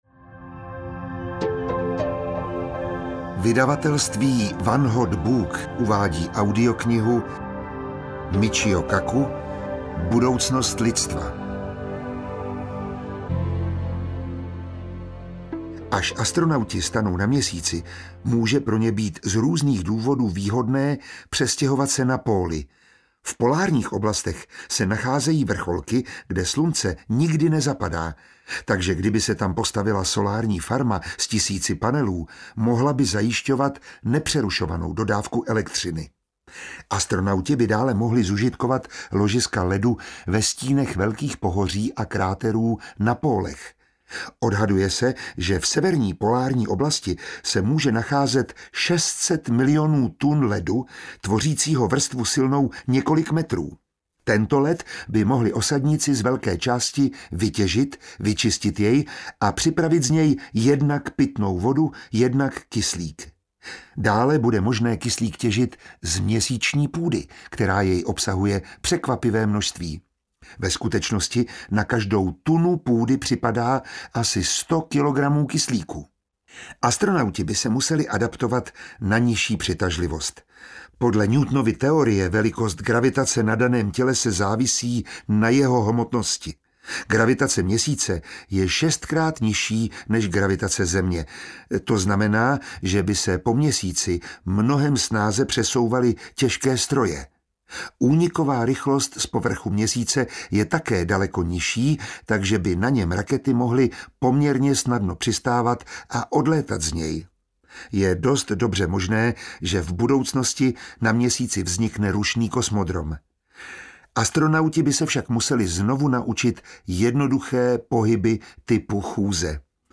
Budoucnost lidstva: Náš úděl mezi hvězdami audiokniha
Ukázka z knihy